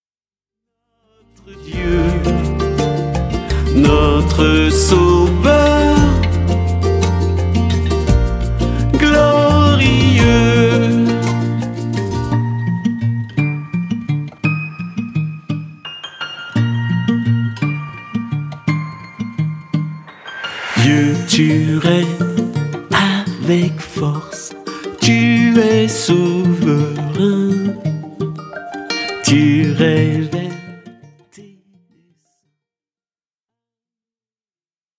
single pop-louange très dansant !